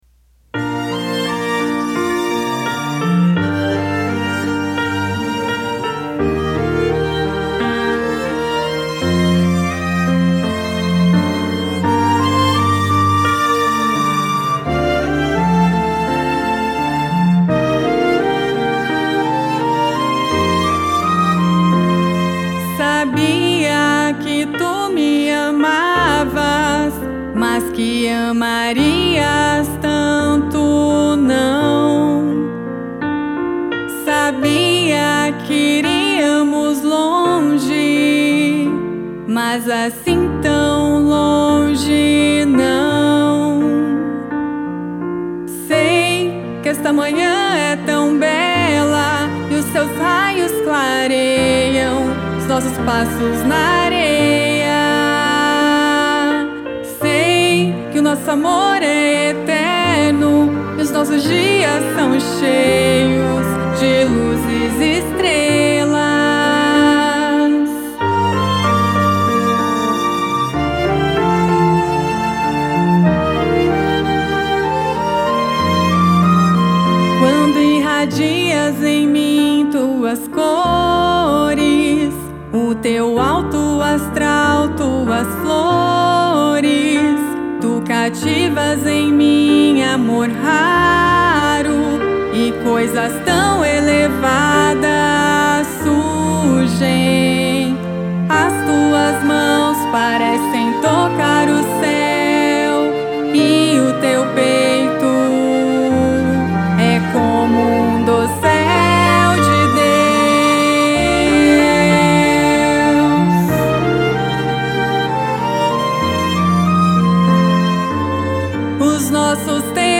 • Arranjo e Piano
• Violino